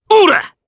Gladi-Vox_Attack3_jp.wav